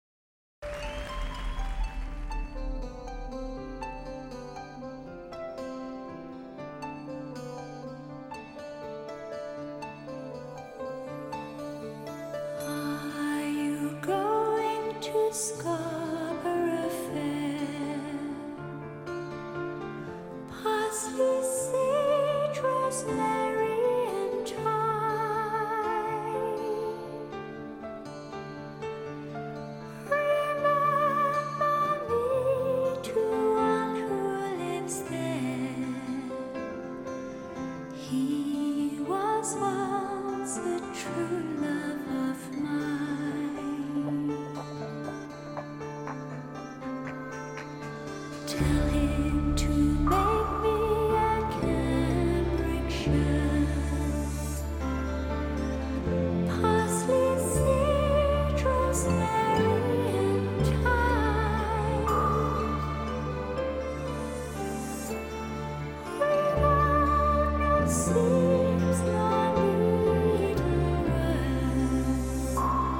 DTS-ES6.1
都是DTS多维立体音场带来的极品体验。